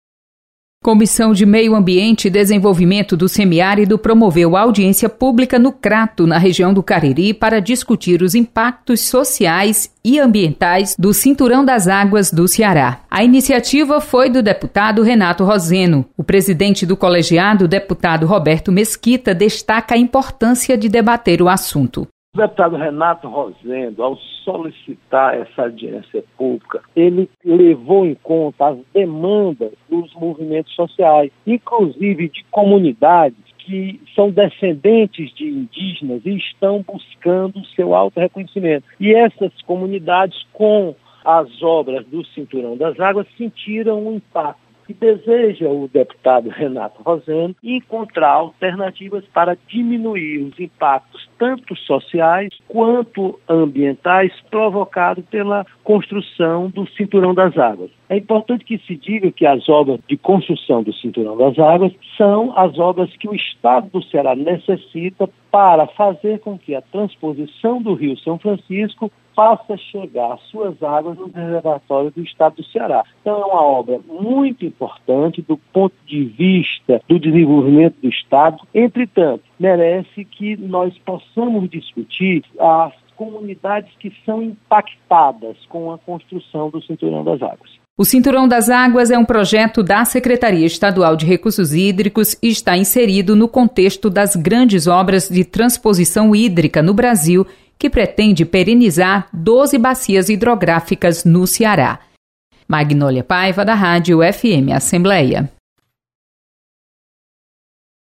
Comissão debate impactos do Cinturão das Águas no Cariri. Repórter